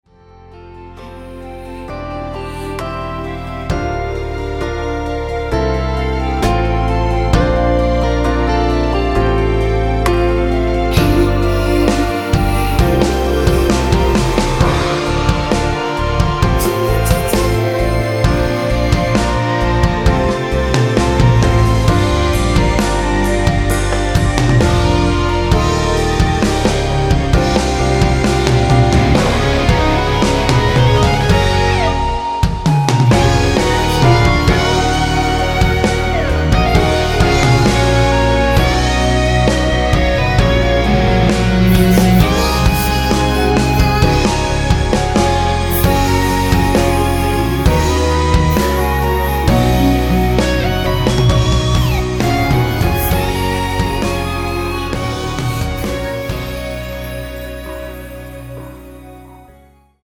원키에서(+4)올린 (1절앞+후렴)으로 진행되는 멜로디와 코러스 포함된 MR입니다.(미리듣기 확인)
앞부분30초, 뒷부분30초씩 편집해서 올려 드리고 있습니다.
중간에 음이 끈어지고 다시 나오는 이유는